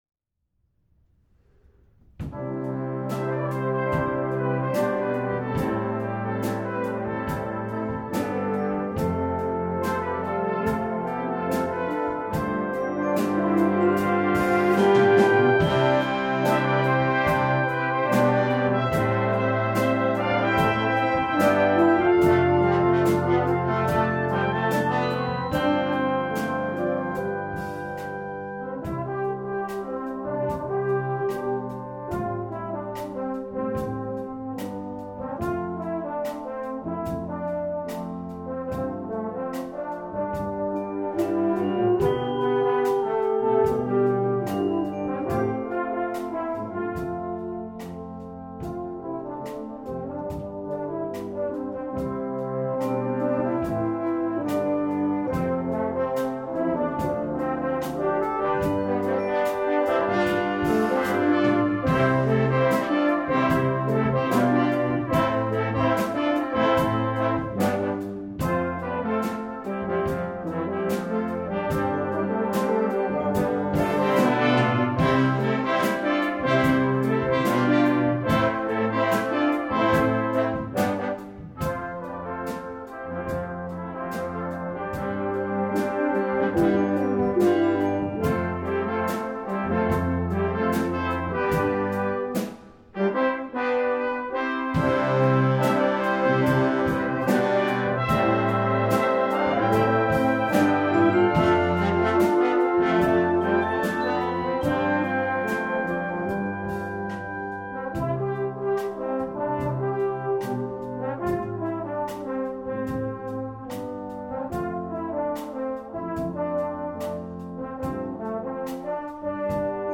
Composition Style: Song Arrangement